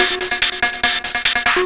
DS 144-BPM B6.wav